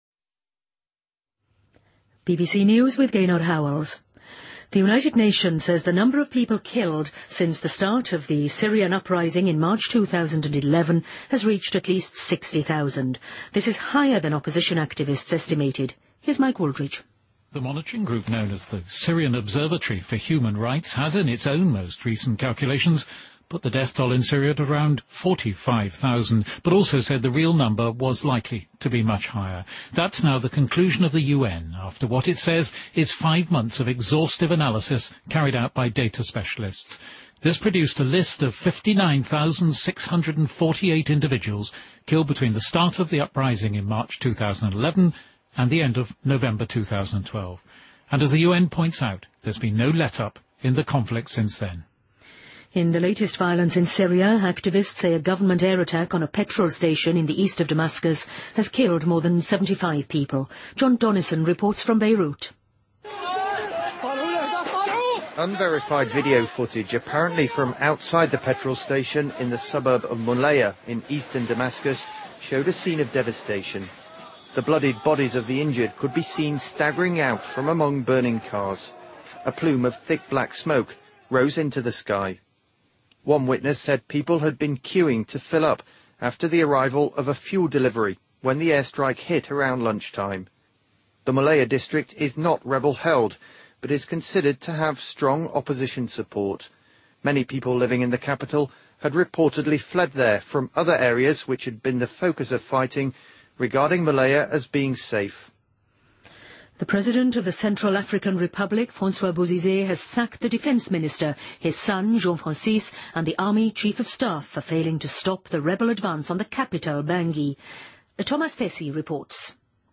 BBC在线收听下载,毛里塔尼亚最近禁止使用塑料购物袋